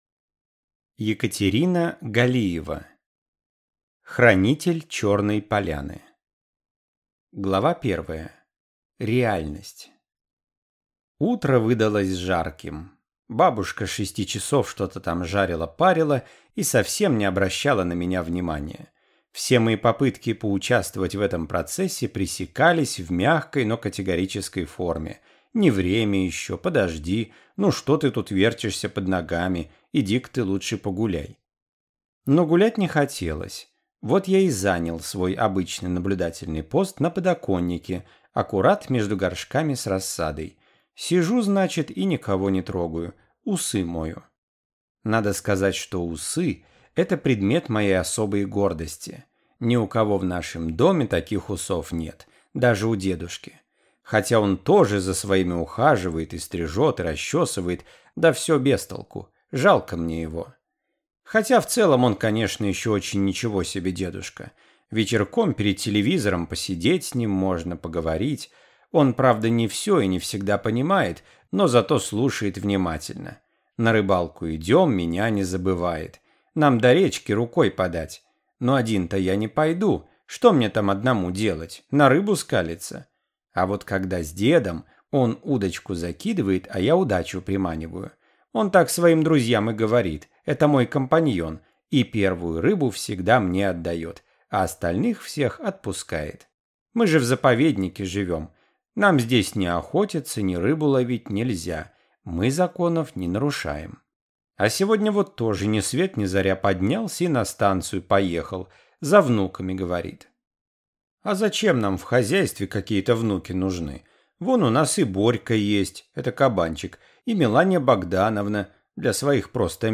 Аудиокнига Хранитель Чёрной поляны | Библиотека аудиокниг